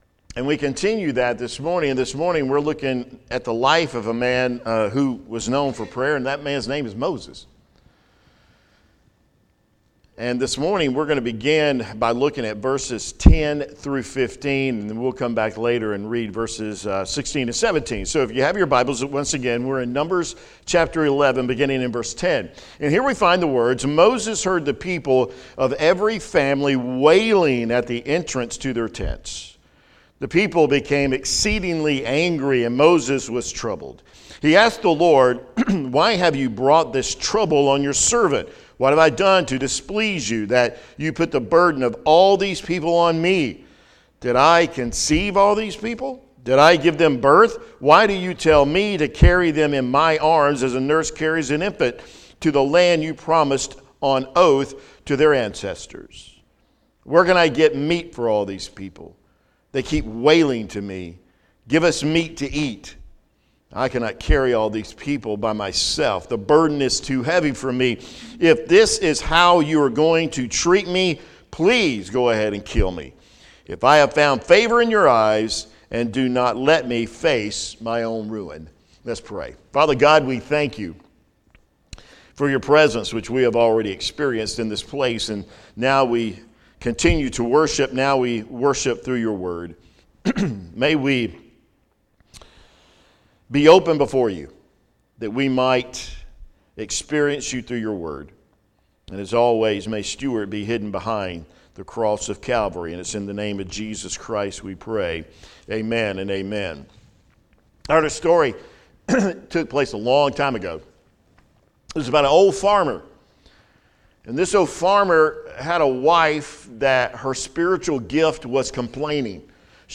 Lake Pointe Baptist Church Weekly Messages